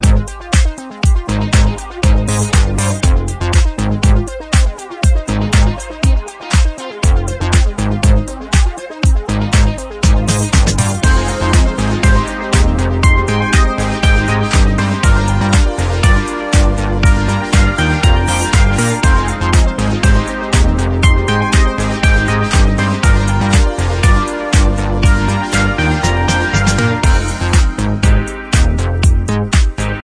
fresh nu disco
Disco House